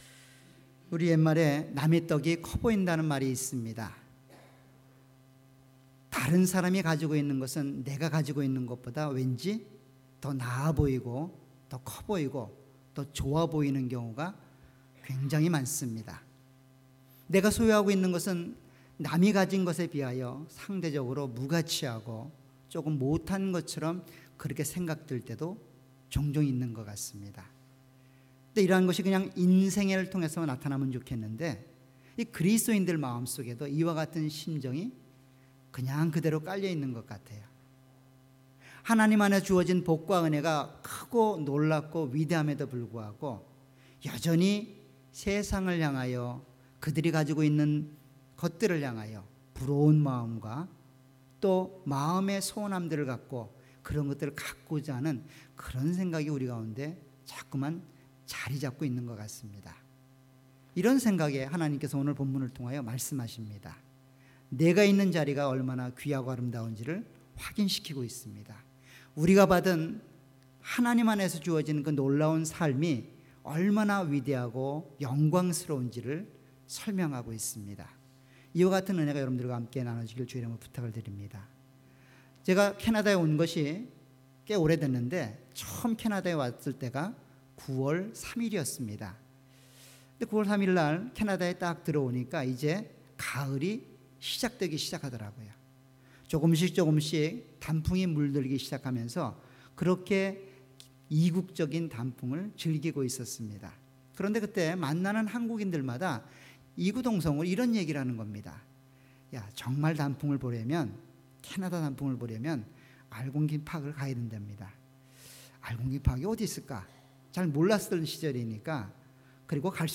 All Sermons
Series: 주일예배.Sunday Topic: 구원.Salvation